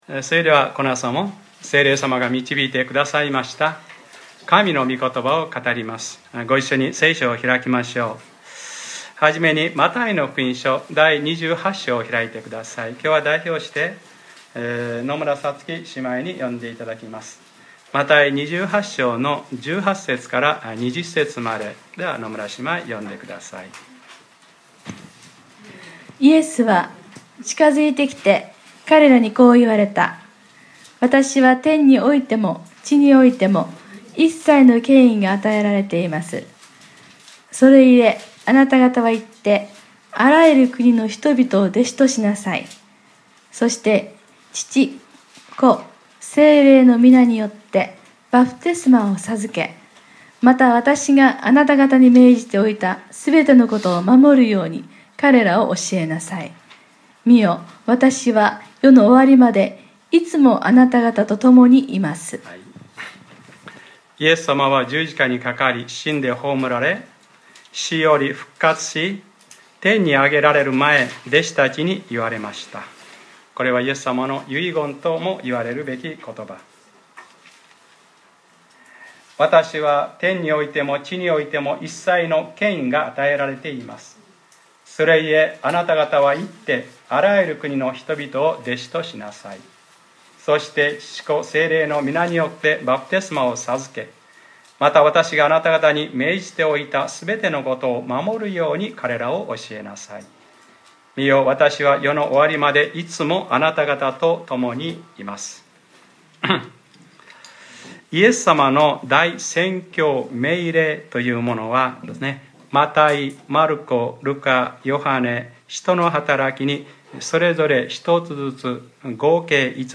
2014年10月19日（日）礼拝説教 『バプテスマ』